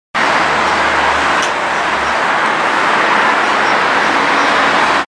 • AMBIENT ( Rumore ambientale come traffico, folle, passi, meteo, aereo, treno, rumori ambientali con caratteristiche molto variabili nel tempo ), ( a.27 ).
A.27 Ambient
traffico.mp3